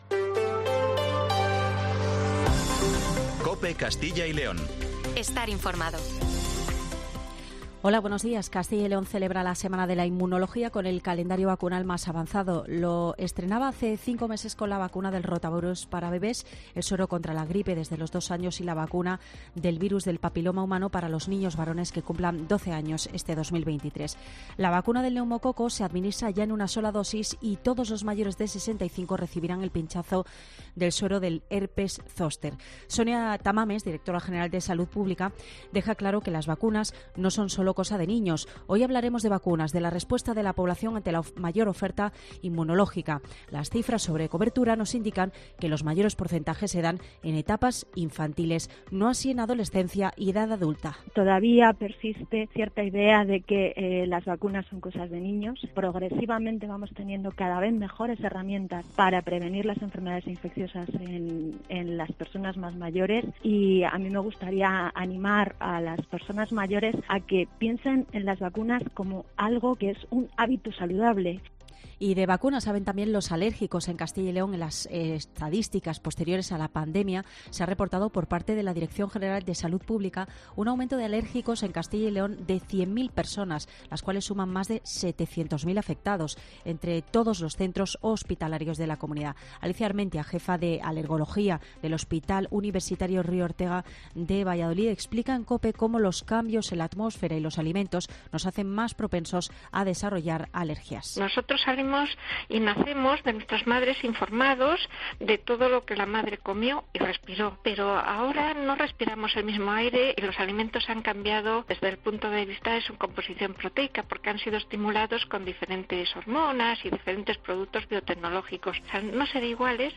Informativo Matinal 7:50